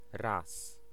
Ääntäminen
Vaihtoehtoiset kirjoitusmuodot (Skotlannin englanti) ance (rikkinäinen englanti) wunst (rikkinäinen englanti) wonce Synonyymit erstwhile Ääntäminen US : IPA : [wʌns] Tuntematon aksentti: IPA : /wʌn(t)s/